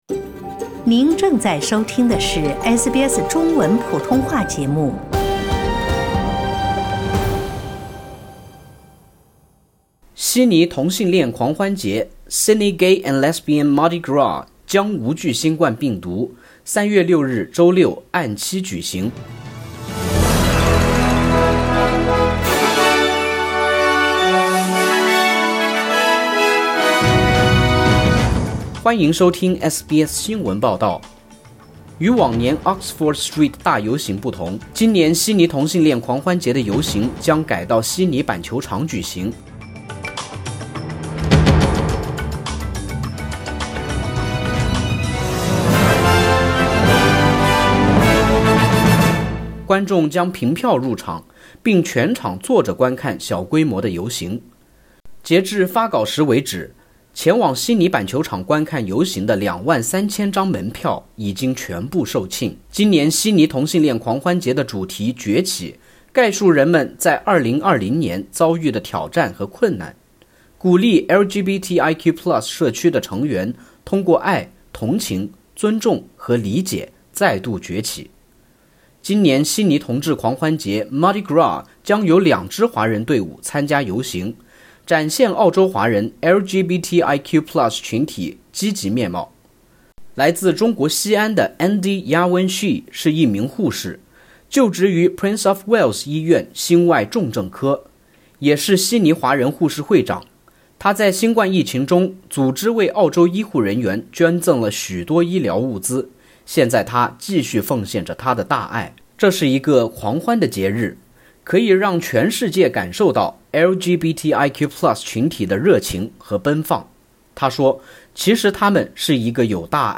悉尼同性恋狂欢节（Sydney Gay and Lesbian Mardi Gras）将如期于3月6日（周六）举行。 （点击上图收听报道）